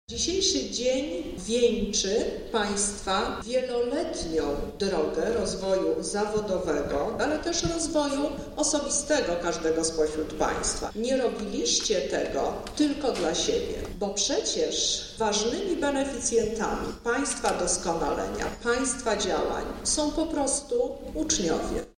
Mówi Misiuk